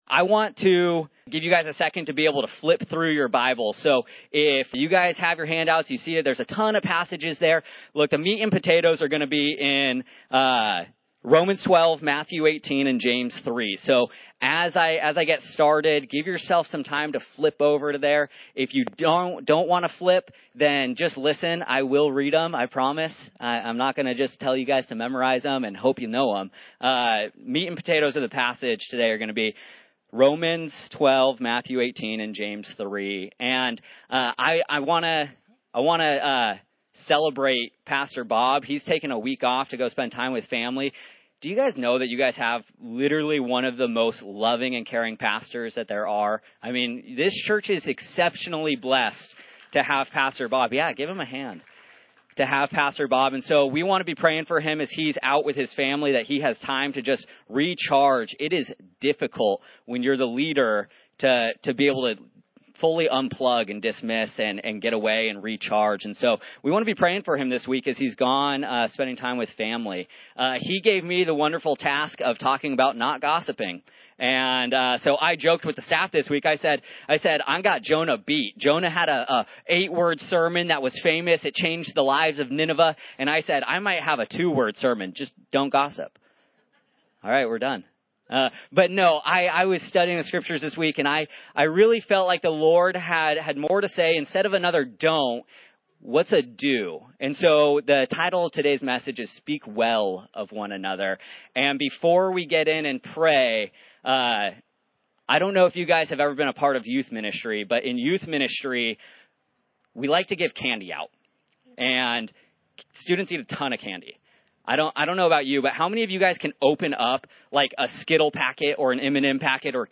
Various Passages Service Type: Sunday This Sunday we’ll be talking about what it means to Speak Well of One Another!